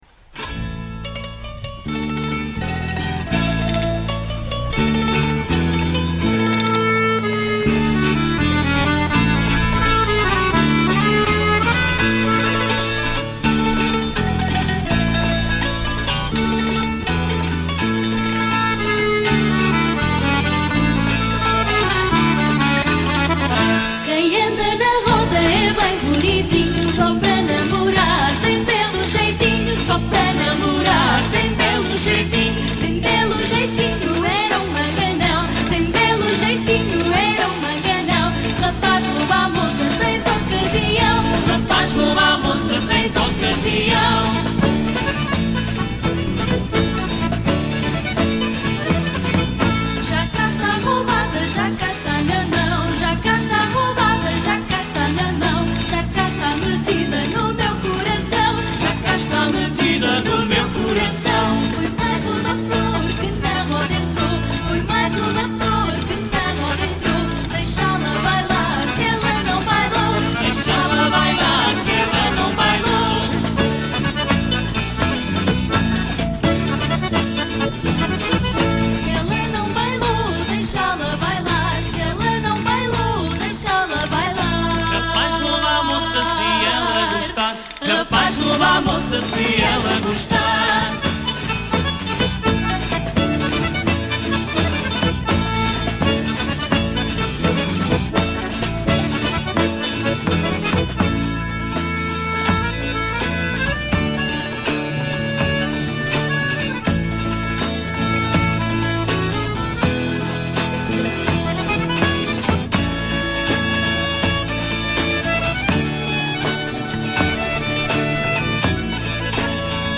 JOGOS DE RODA
Deslocam-se, ligeiros, no sentido contrário aos ponteiros do relógio, invertendo-o a meio da introdução instrumental. O sentido deverá mudar no início de cada quadra.